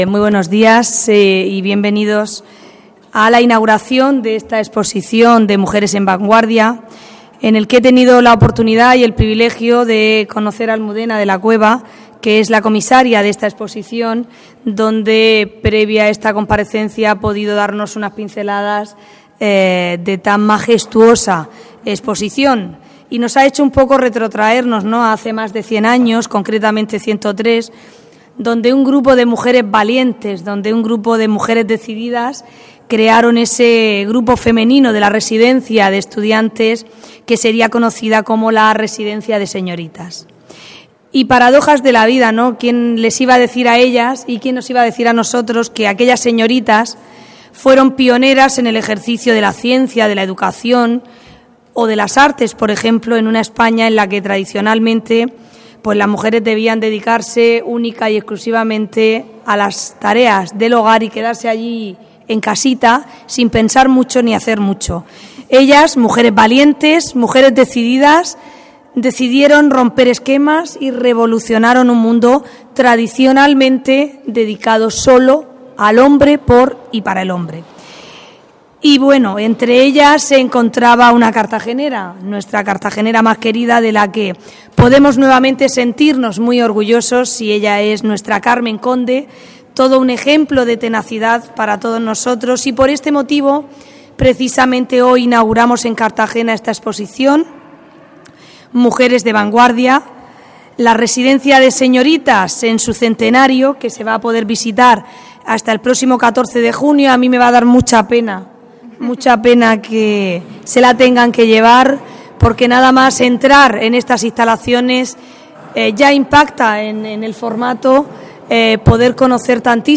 Audio: Rueda de Prensa inauguraci�n Exposici�n Mujeres en Vanguardia (MP3 - 12,07 MB)